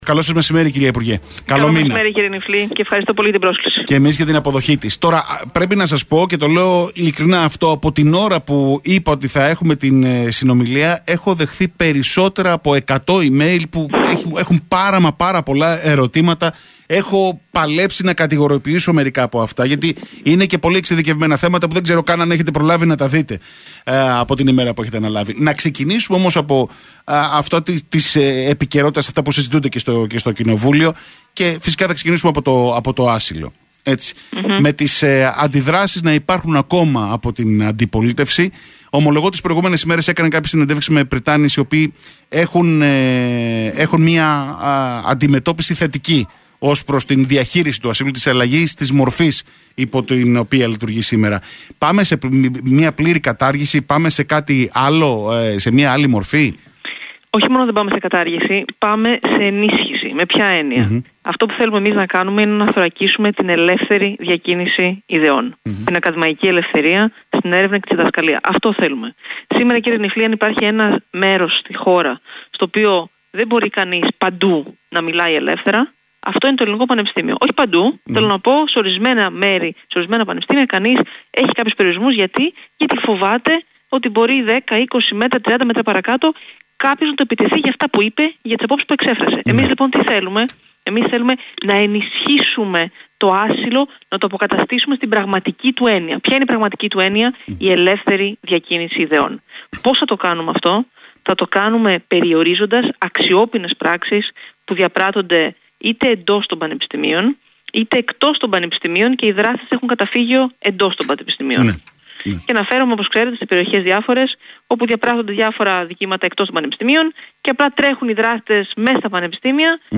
ΑΚΟΥΣΤΕ ΤΗ ΣΥΝΕΝΤΕΥΞΗ: Για το αν δρομολογείται κατάργηση του ασύλου Όχι μόνον δεν πάμε σε κατάργηση, αλλά πάμε σε ενίσχυση του ασύλου.